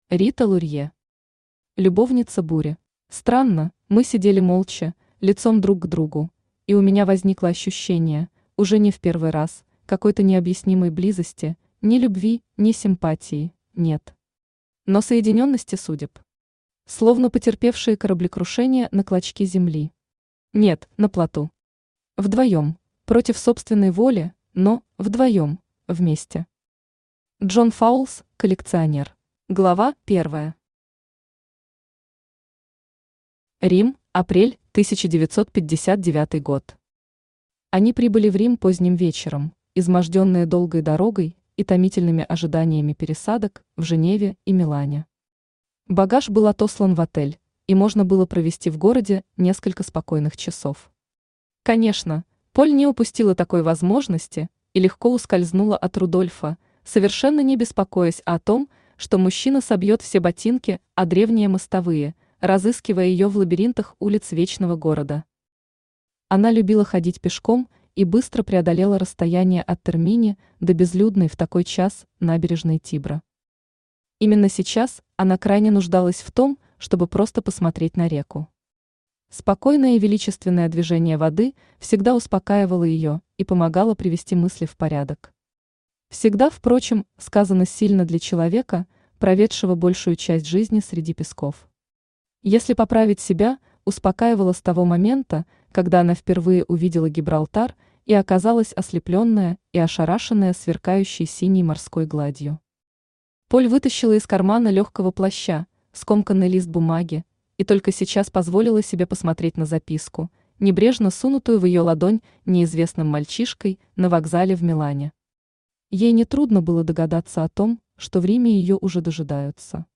Читает: Авточтец ЛитРес
Аудиокнига «Любовница бури».